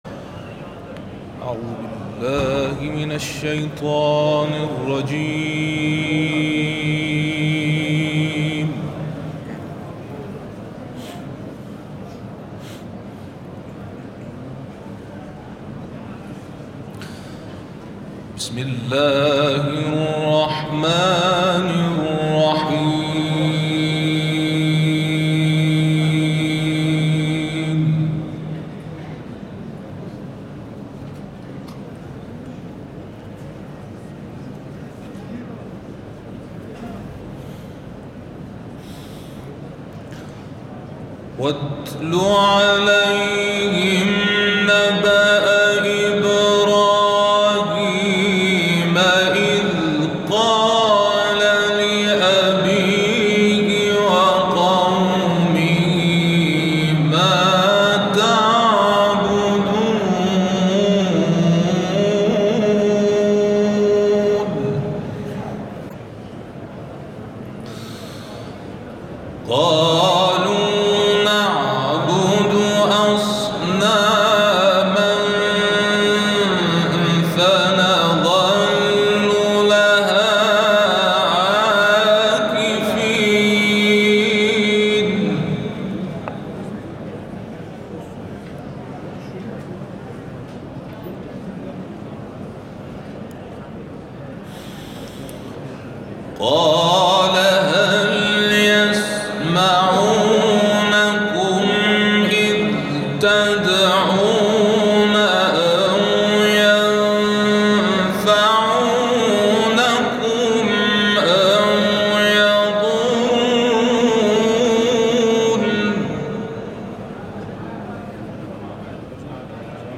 تلاوت